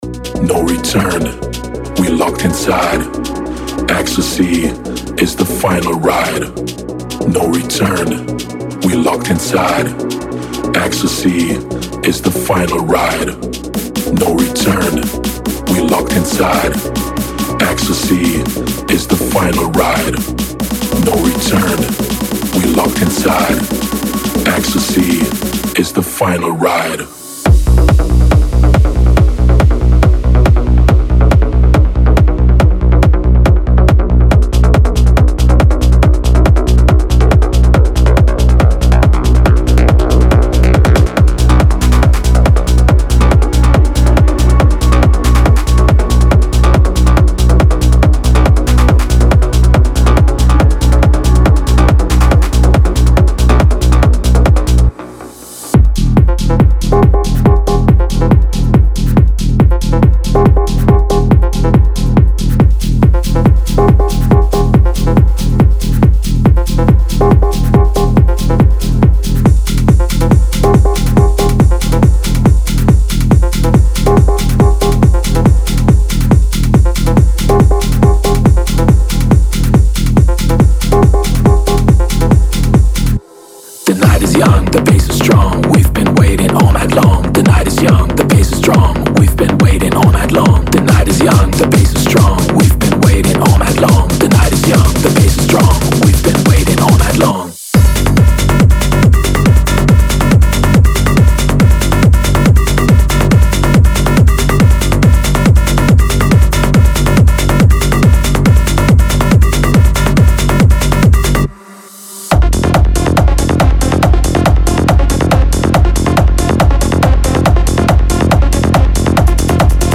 Type: Samples
• 110 Kick Drum One-Shots
• 140 Drum Loops (Recorded at 130, 135, 140 BPM)
• 22 Vocal Phrases @140 BPM (Wet and Dry Versions)